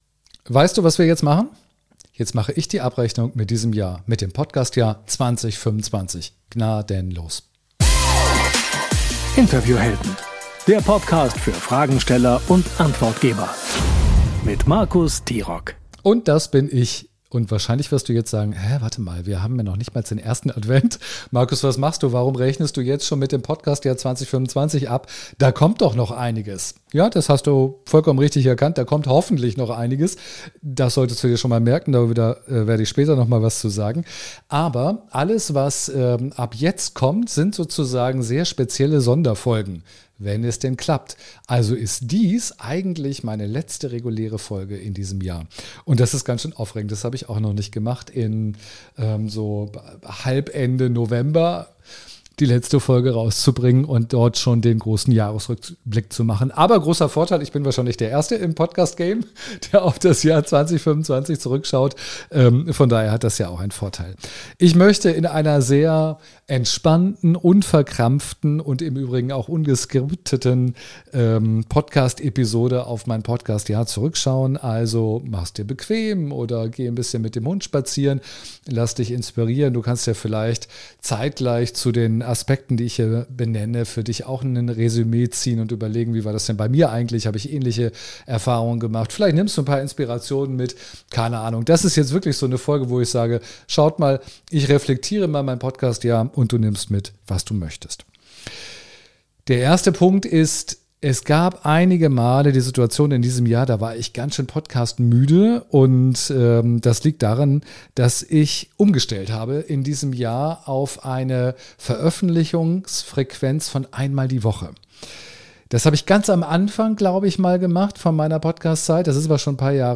Beschreibung vor 4 Monaten In dieser Soloepisode ziehe ich mein persönliches Fazit aus 25 Stunden Podcastproduktion, 15 Interviews und einem Jahr, in dem meine Masterclass im Zentrum meiner Arbeit stand.